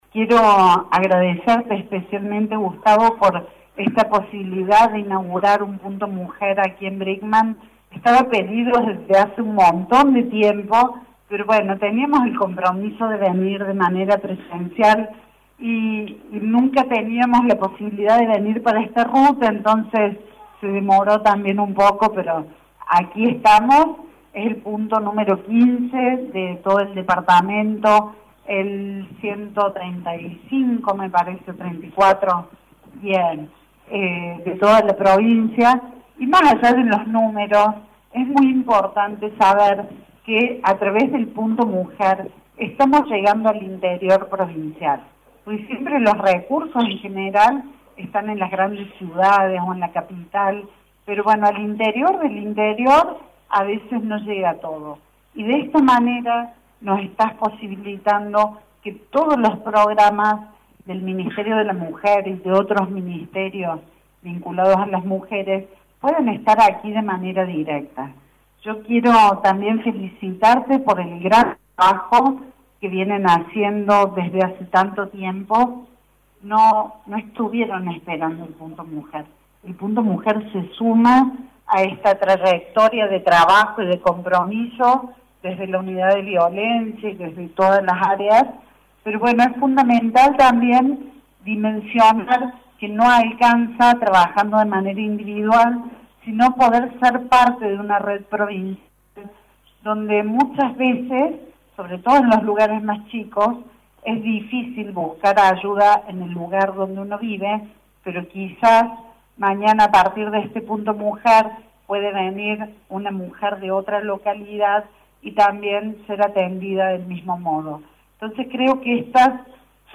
Con la presencia de la ministro de la Mujer Claudia Martínez, el Municipio y el Gobierno Provincial dejaron inaugurado el Punto Mujer N° 134 en la ciudad de Brinkmann.